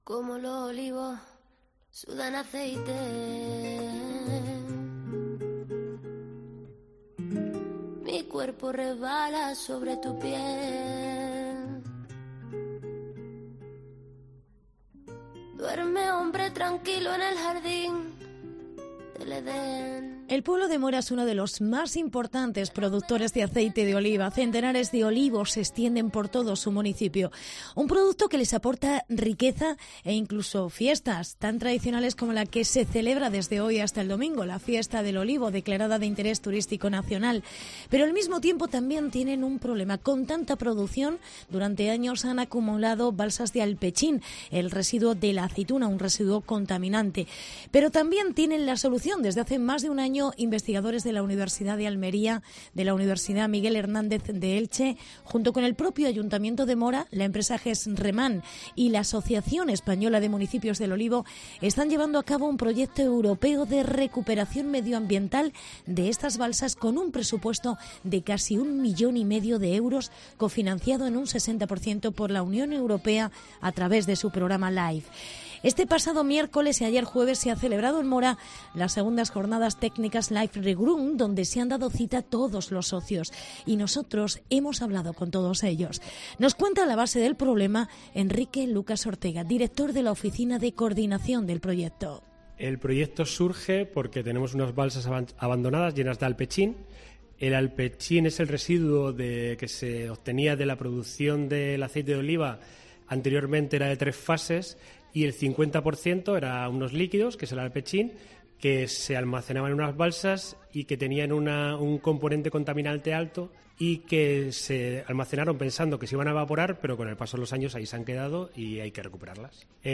AUDIO: 2ª JornadasLife Regrow. Reportaje sobre el proyecto de recuperación de las balsas de alpechín